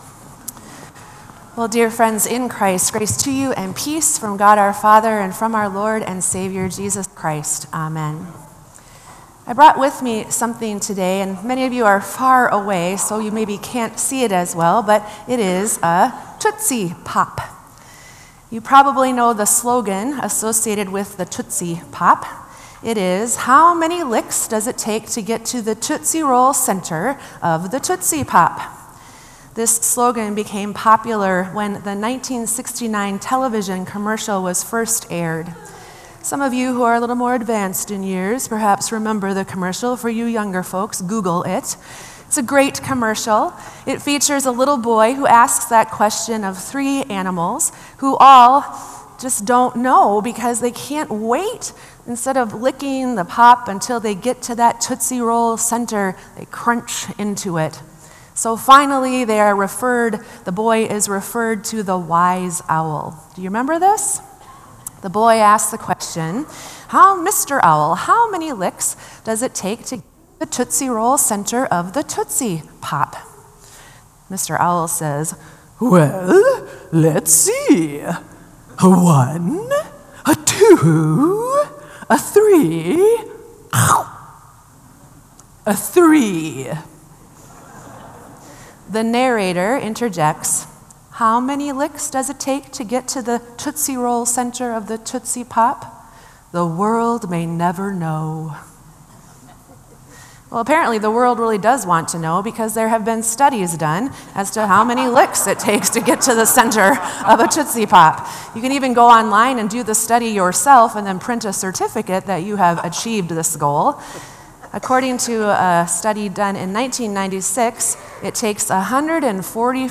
Sermon “Worth The Wait”